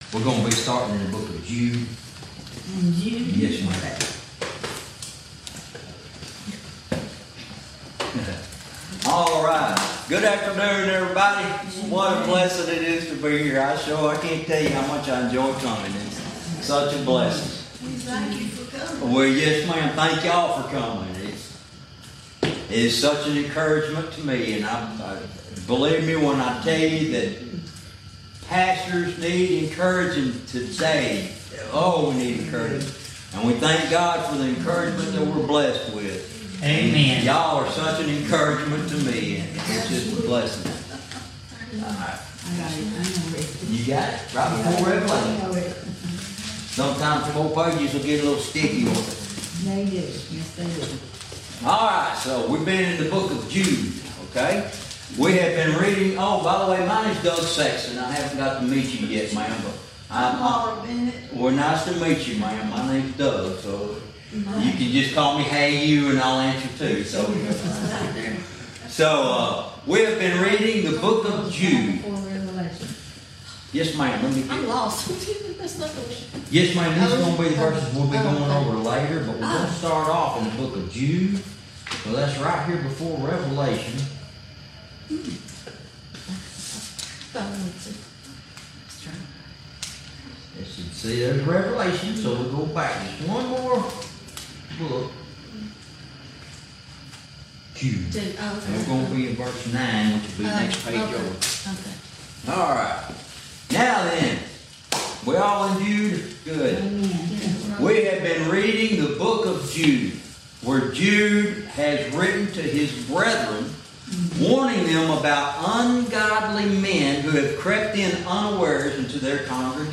Verse by verse teaching - Lesson 31